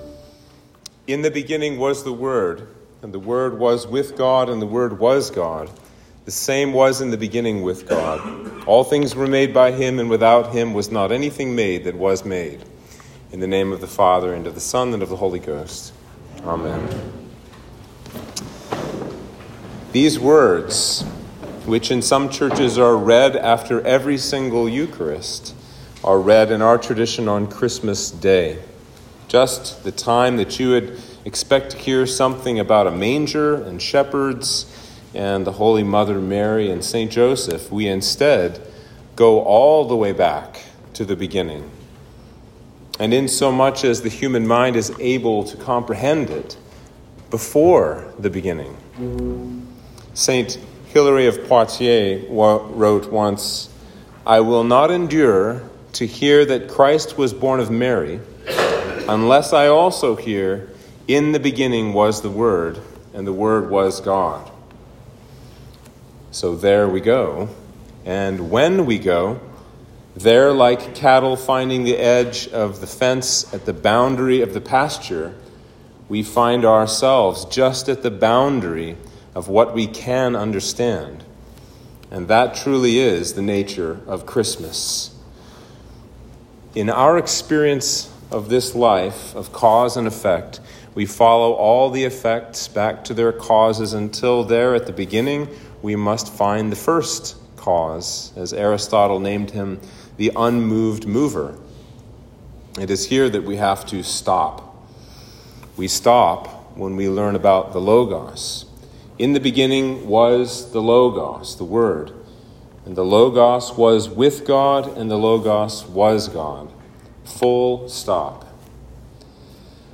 Sermon for Christmas Day